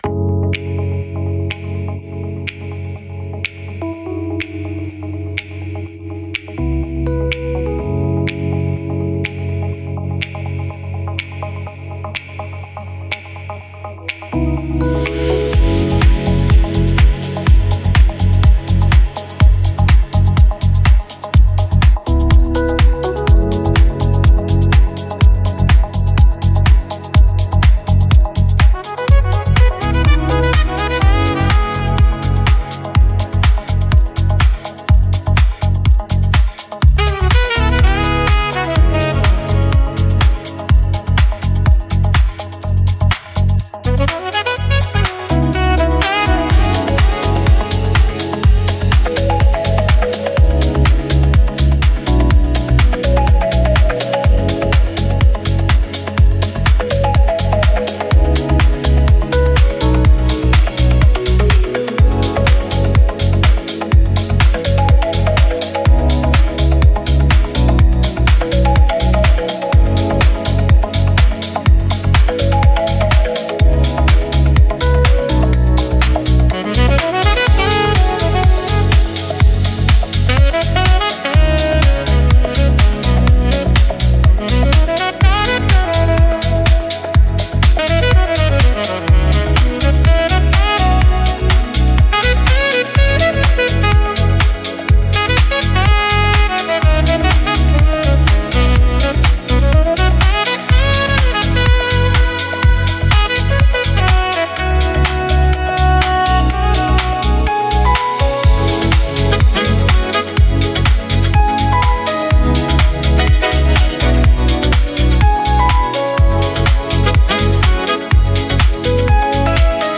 pause_music_03.mp3